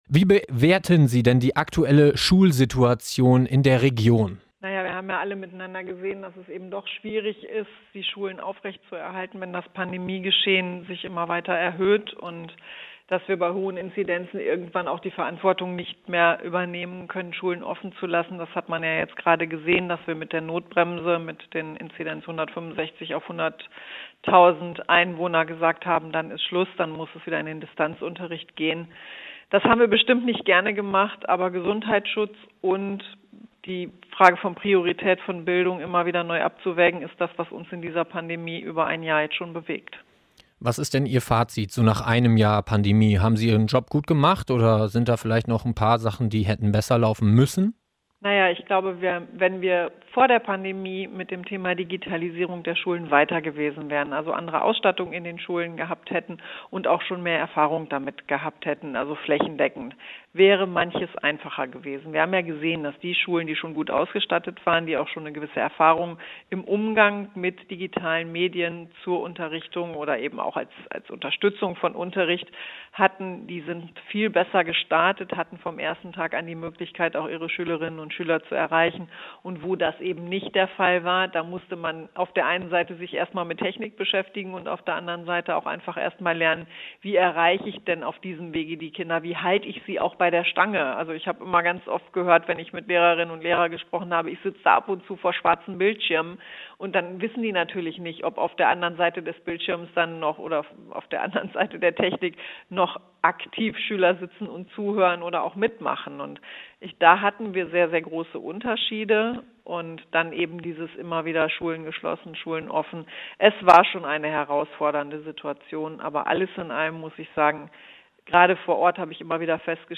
Wir haben in einem großen Interview mit Bildungsministerin Karliczek aus Brochterbeck über Folgen des Lockdowns für Schüler:innen gesprochen.